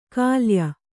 ♪ kālya